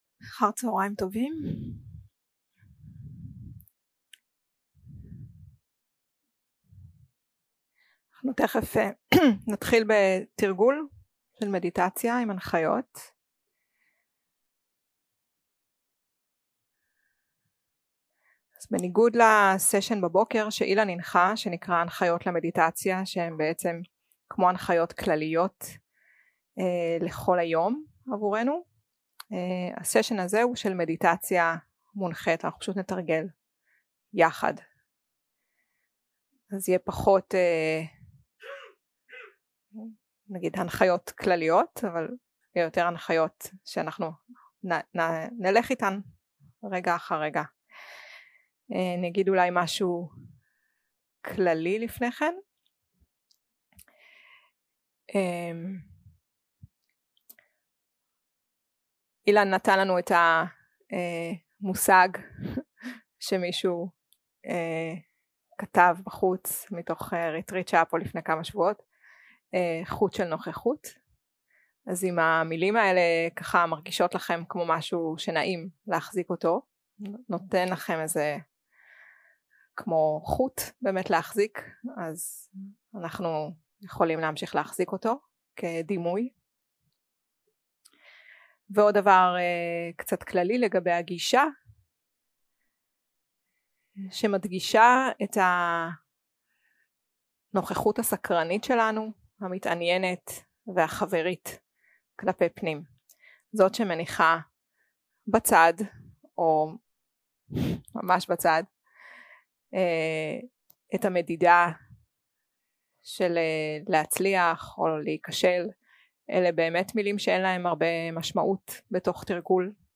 יום 1 – הקלטה 2 – צהריים – מדיטציה מונחית
Dharma type: Guided meditation